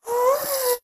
ghast
moan4.ogg